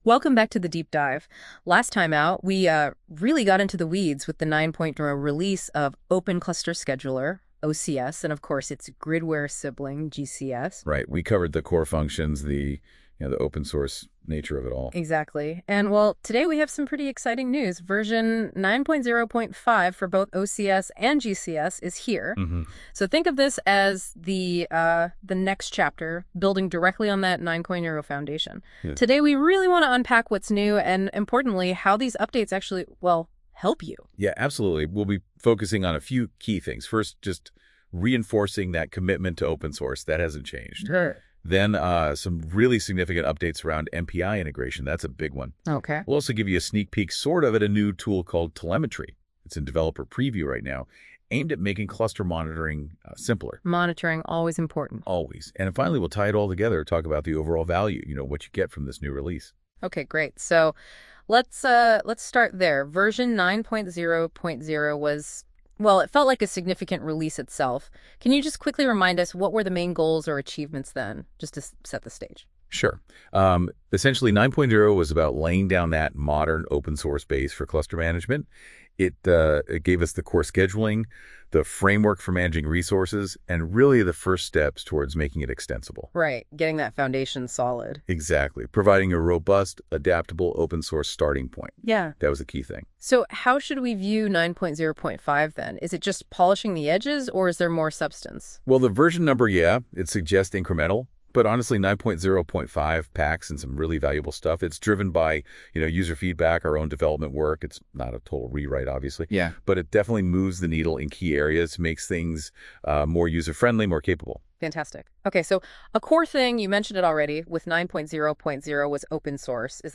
I'm excited to share our latest podcast, which explores the release of Gridware Cluster Scheduler 9.0.5 — built on the new Open Cluster Scheduler 9.0.5! Once again, I turned to NotebookLM to generate a dynamic conversation based entirely on our latest release notes and blog posts.